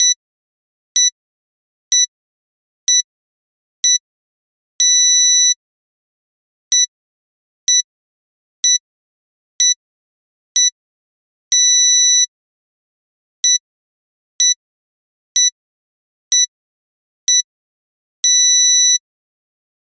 Mono Tone: Ringtone
pips_mono.mp3